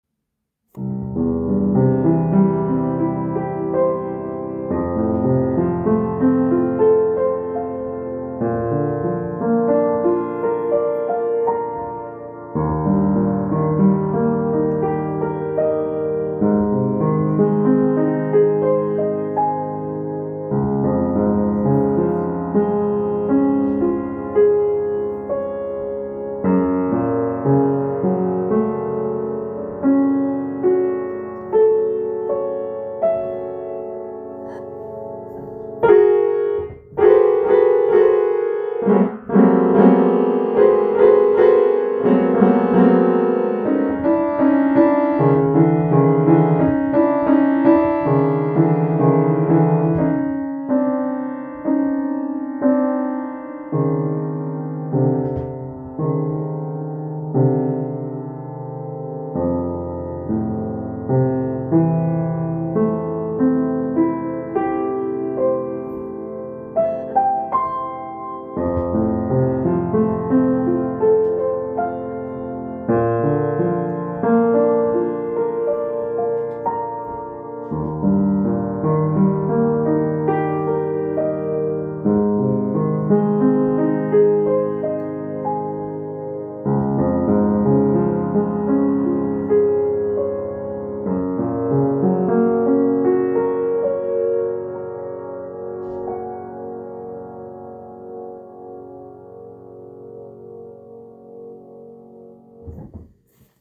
Klavier-Improviation auf den Gabentisch lege.
Erst ist alles easy, dann merkt man, das etwas nicht stimmt, Adrenalin wird ausgeschüttet, Alarm geht los, schnell was essen, dann müde, unkonzentriert und nach einiger Zeit pendelt sich alles wieder ein, wird wieder gut, es geht weiter …